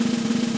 soft-sliderslide.ogg